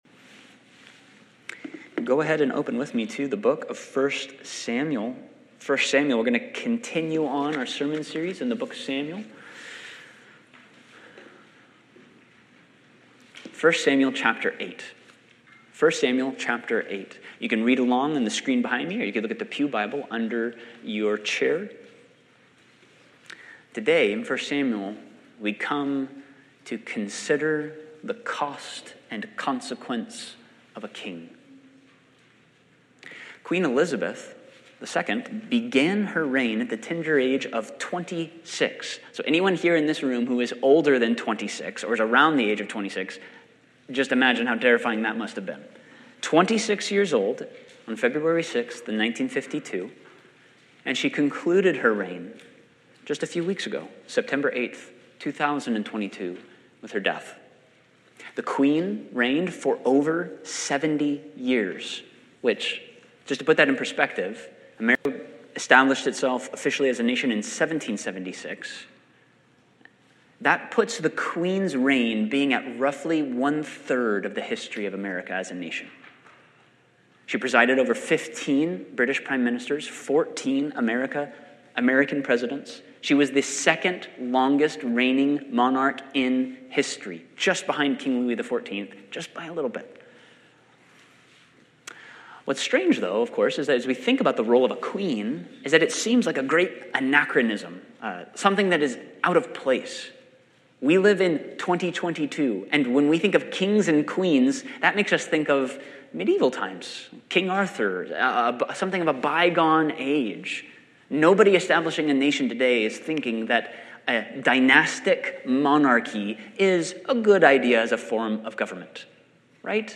Sermons | Quinault Baptist Church